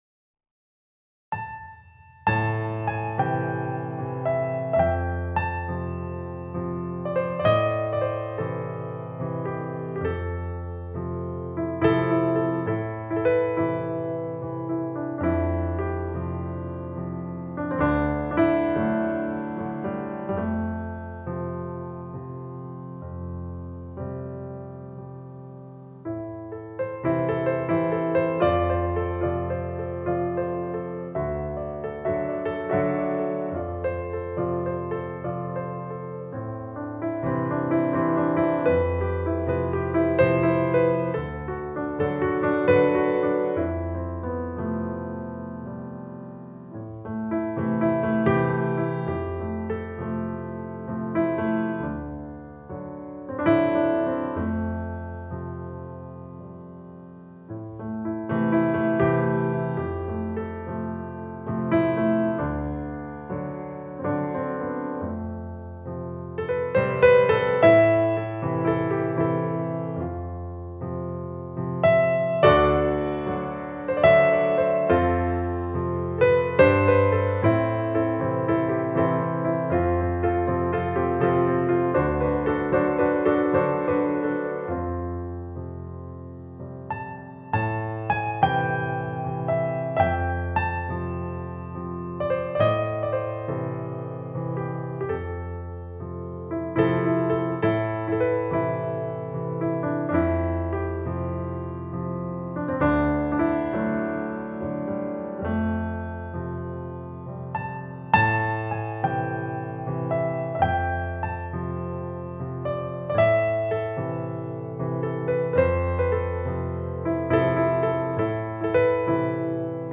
آرامش بخش , پیانو , مدرن کلاسیک , موسیقی بی کلام